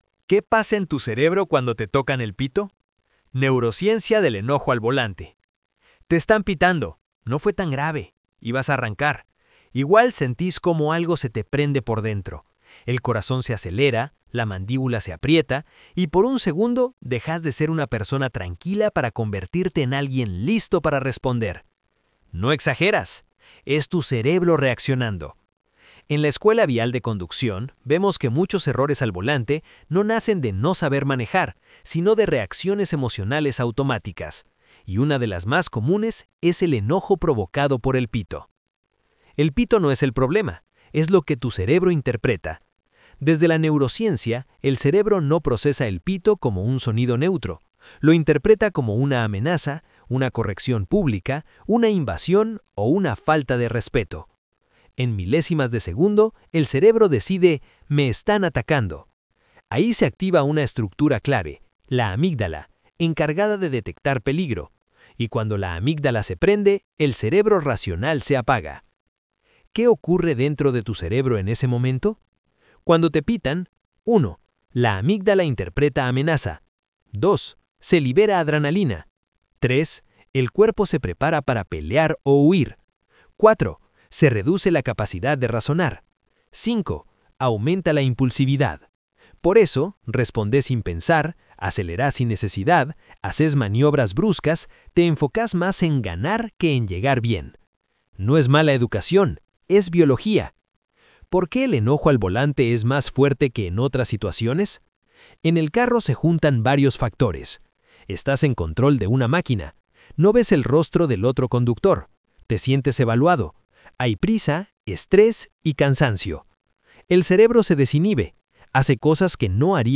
No te preocupés, acá te lo leemos.